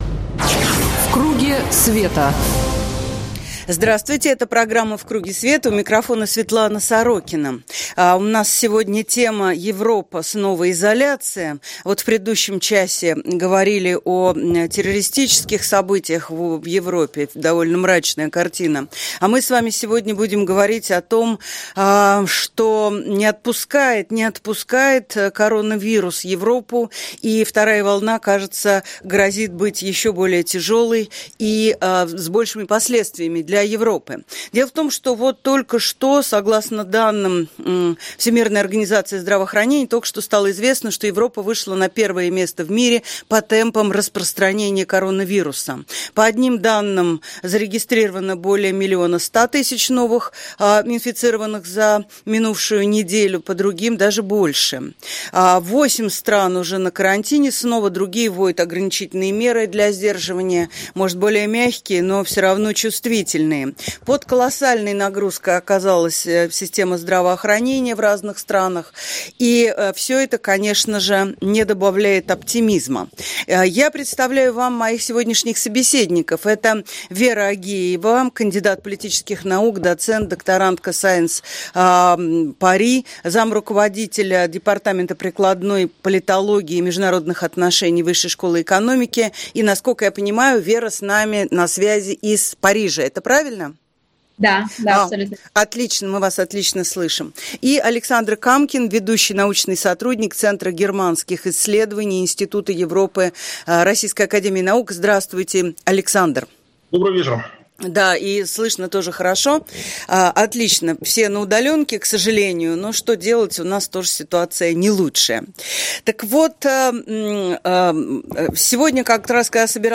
Все на удаленке, к сожалению, но что делать, у нас тоже ситуация не лучшая.